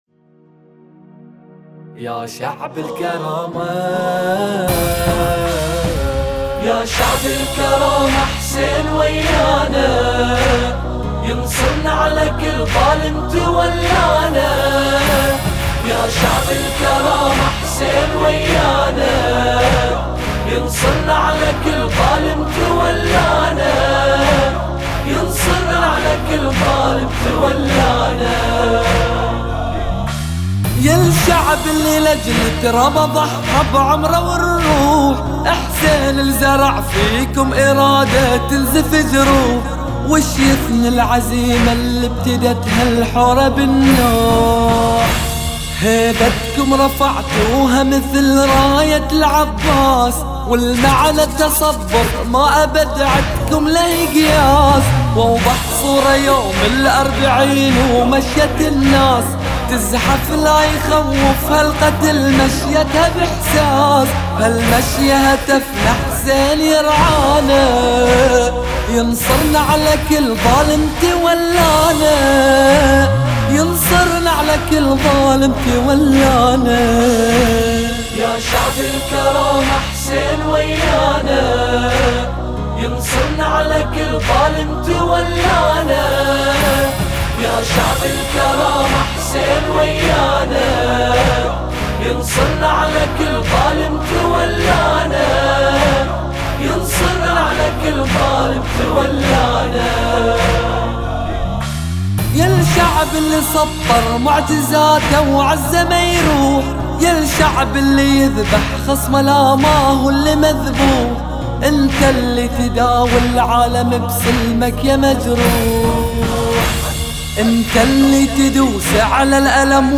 نشيد
أناشيد بحرينية